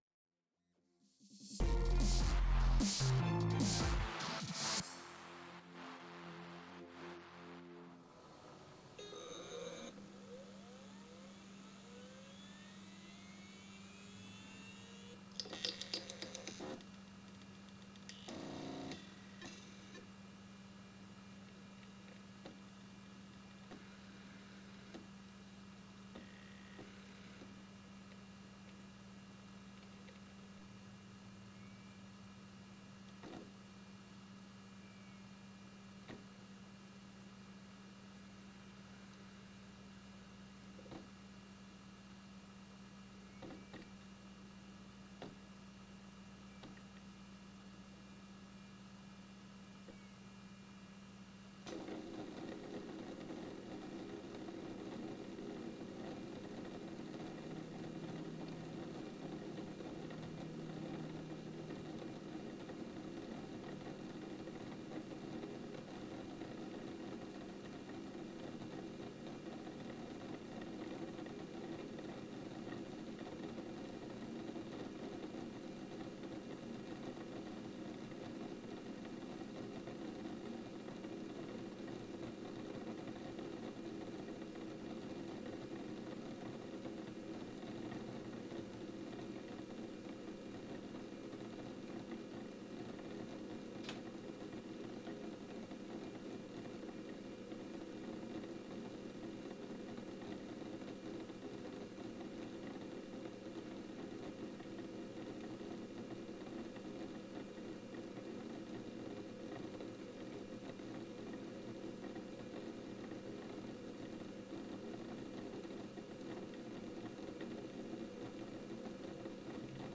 HDD noise levels table/list